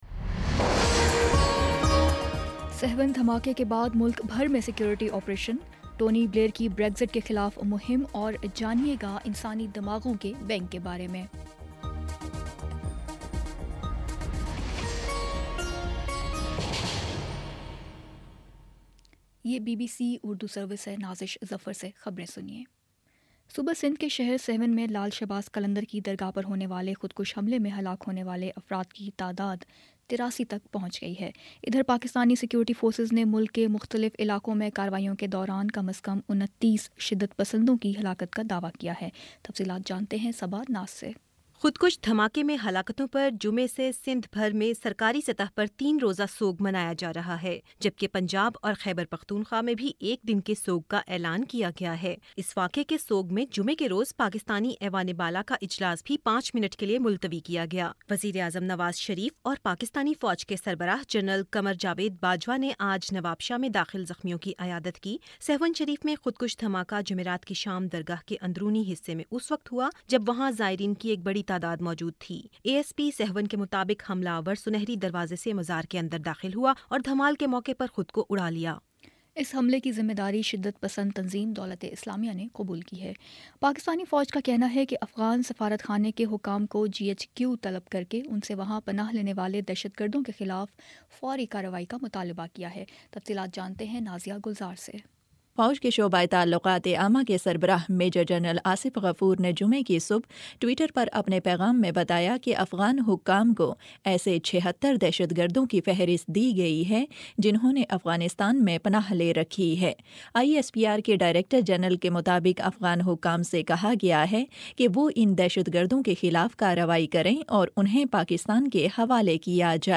فروری 17 : شام چھ بجے کا نیوز بُلیٹن